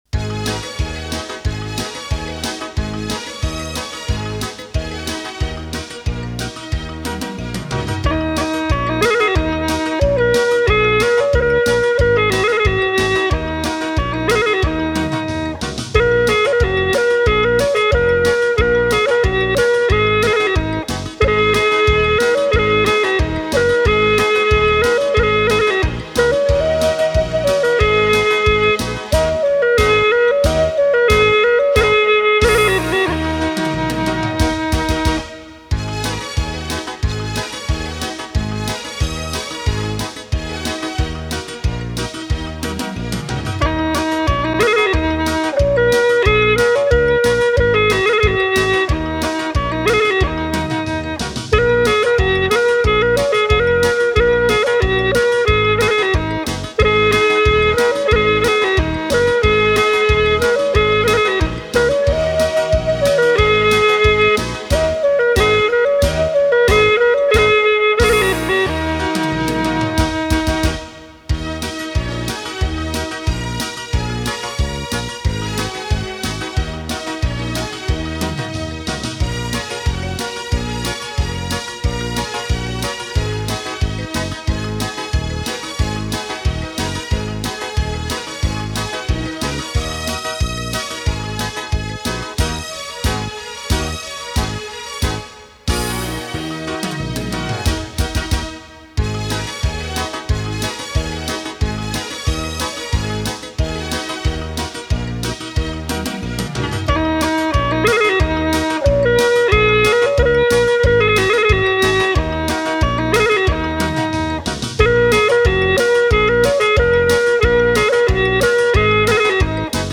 亲切感人，温馨婉转，引人入胜！